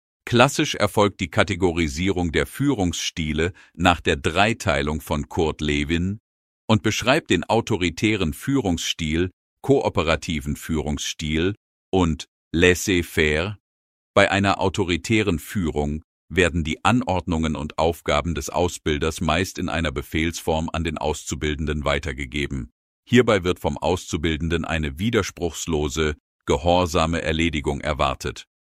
Folge 5 der Podcast-Reihe „Lektion k„, gesprochen vom virtuellen Avatar Dr. Frederick von Bralchow.